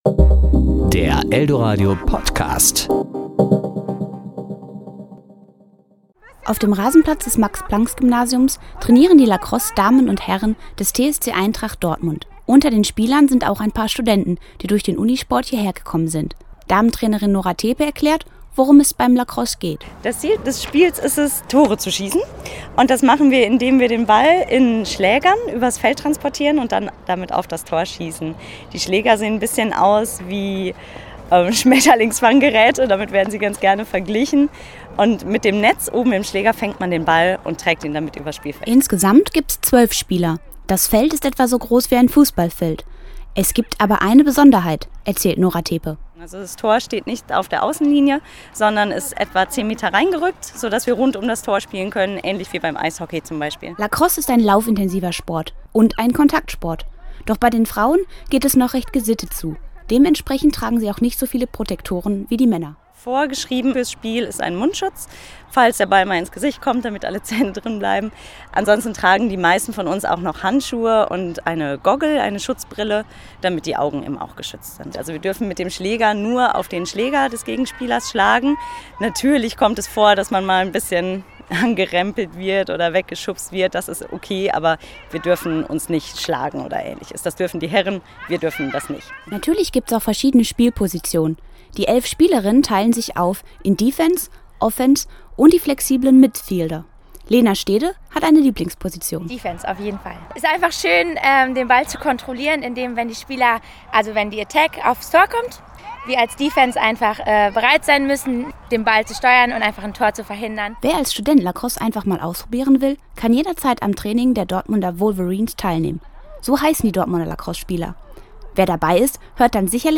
Serie: Beiträge